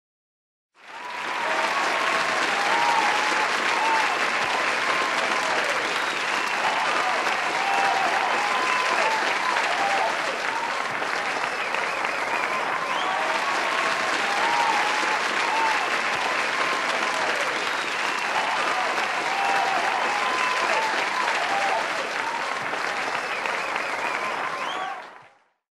Applause Crowd Cheering sound effect